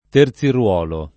[ ter Z ir U0 lo ]